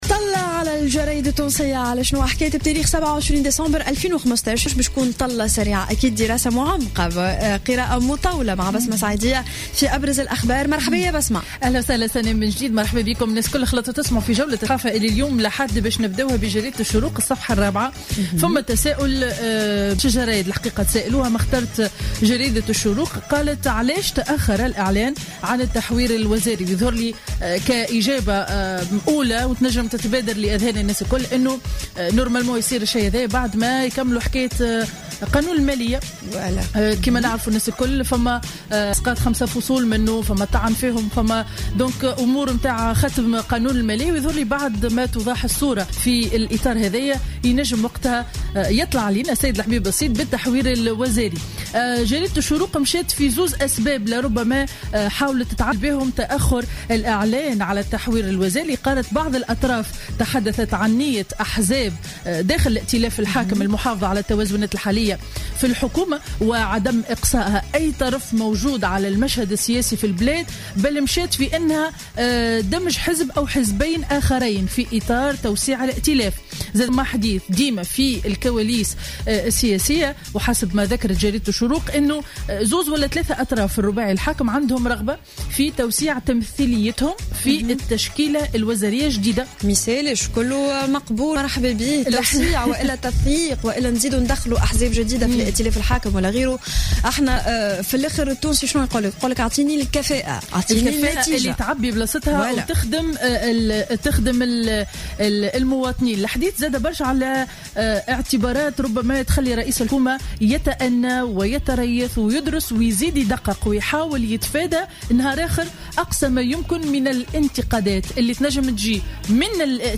Revue de presse du Dimanche 27 Décembre 2015